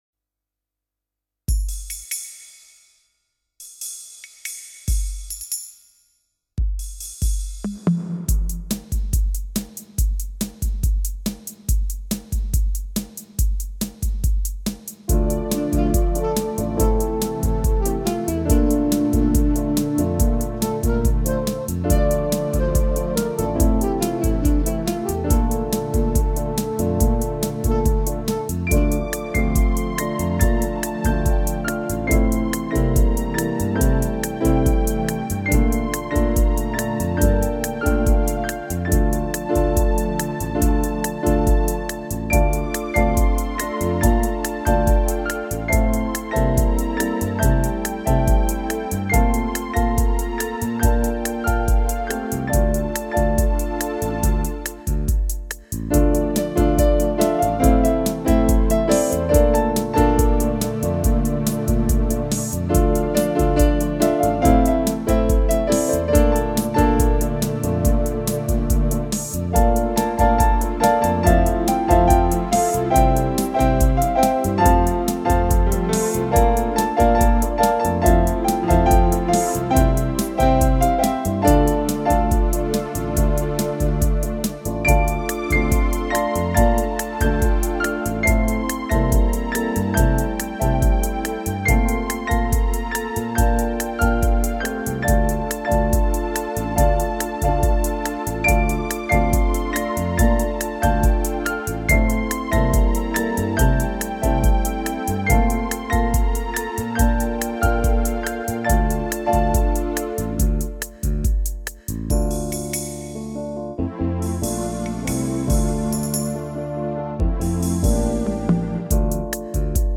I now use a Casio MZ-2000 and my new Yamaha Genos V2 76-key Synthesiser/Arranger/Workstations to add to my digital recording studio.